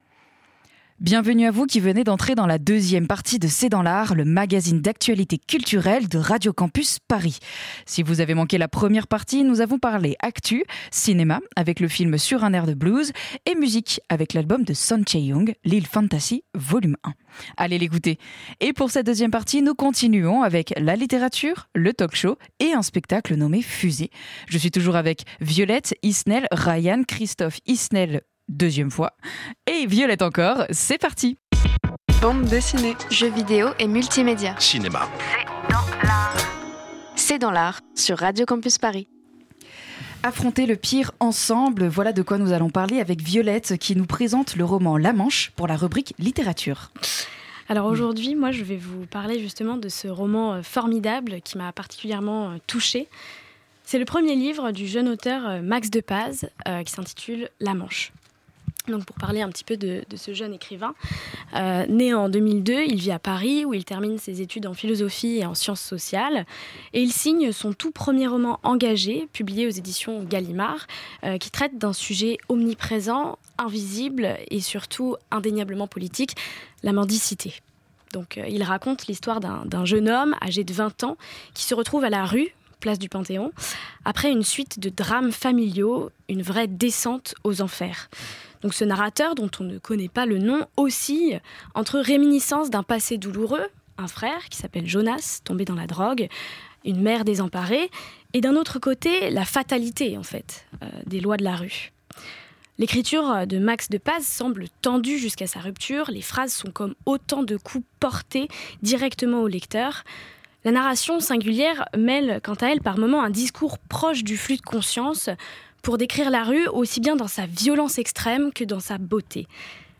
C’est dans l’art, l’émission d'actualité culturelle de Radio Campus Paris.